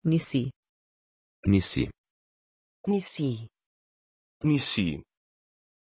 Aussprache von Nici
pronunciation-nici-pt.mp3